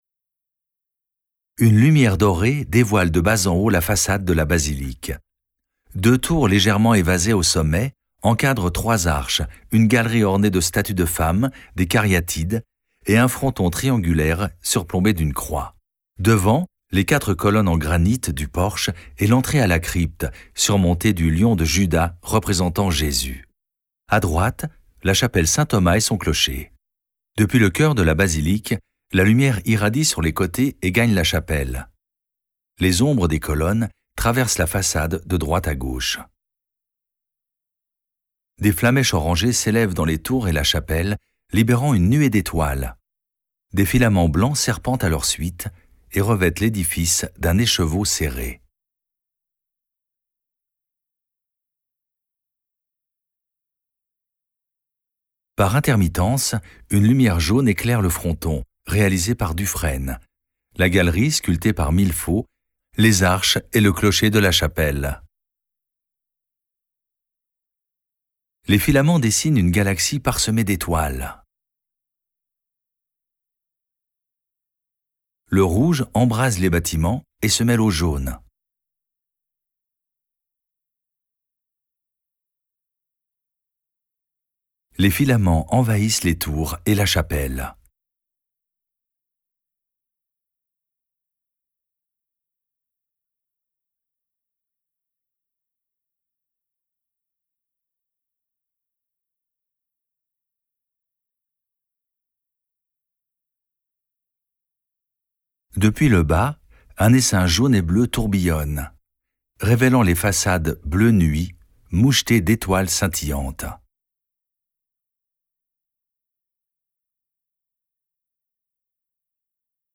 Audiodescription spectacle de Fourvière (18 min).mp3